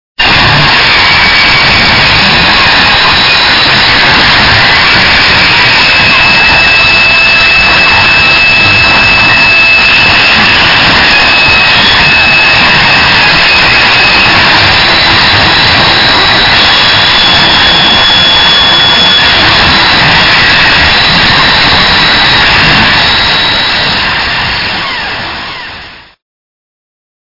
コメント ハーシュに、ヴァイオレントに、そしてセンシブルに変幻する驚異のノイズミュージック！